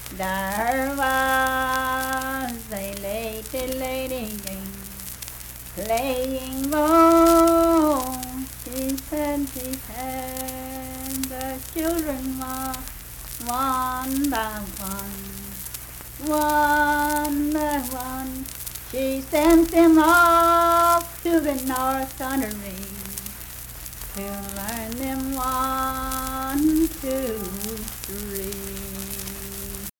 Unaccompanied vocal music
Performed in Big Creek, Logan County, WV.
Voice (sung)